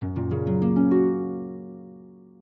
soundblocks_harp1.ogg